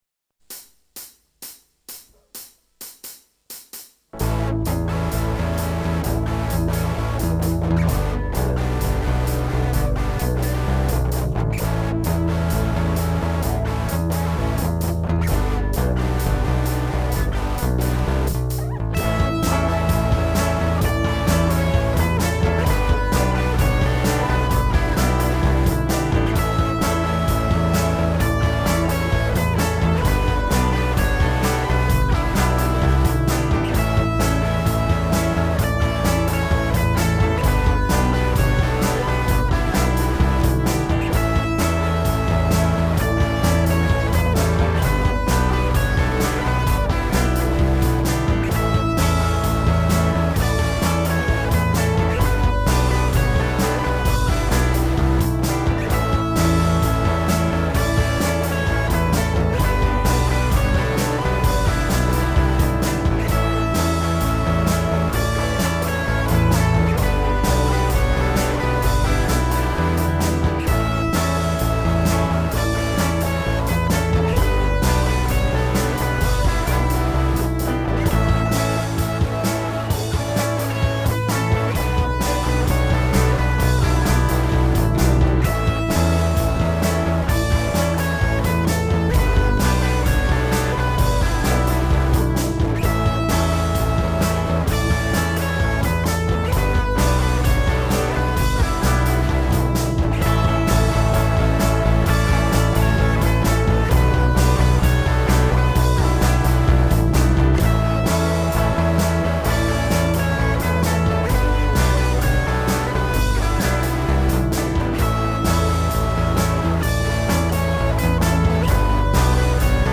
self remix album
BOSSのドラムマシン大活躍。